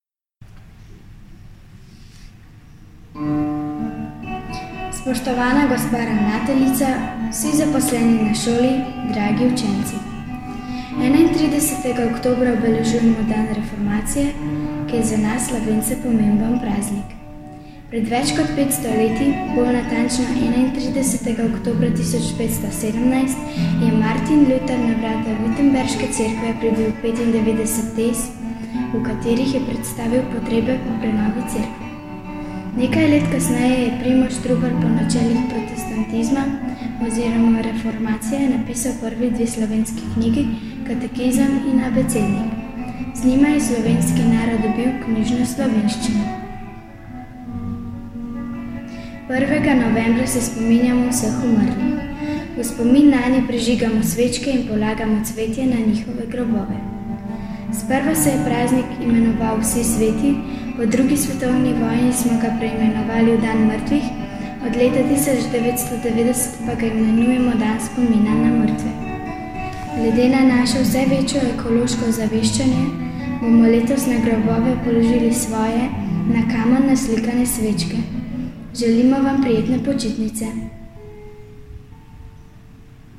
Nagovor.mp3